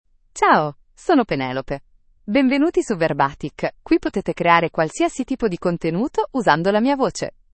PenelopeFemale Italian AI voice
Penelope is a female AI voice for Italian (Italy).
Voice sample
Listen to Penelope's female Italian voice.
Penelope delivers clear pronunciation with authentic Italy Italian intonation, making your content sound professionally produced.